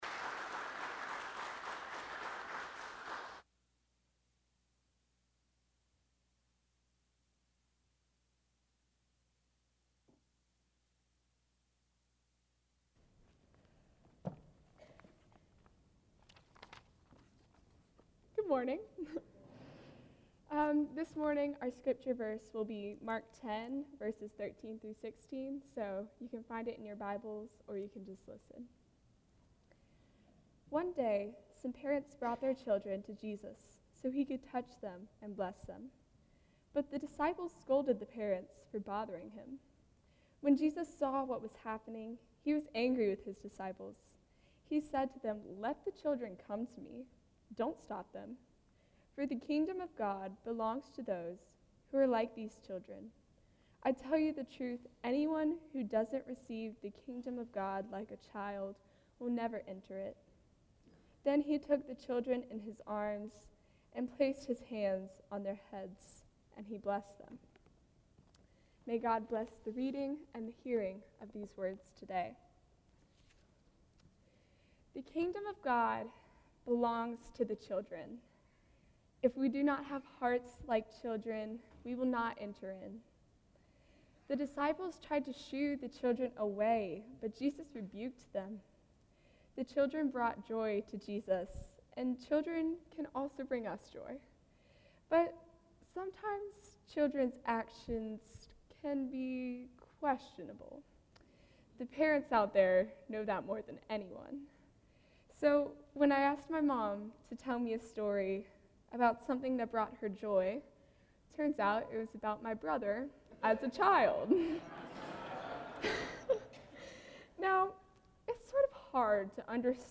Youth Sunday 2018 from Yates Baptist Church on Vimeo.
Mark 10:13-16 Service Type: Guest Preacher Youth Sunday 2018 from Yates Baptist Church on Vimeo .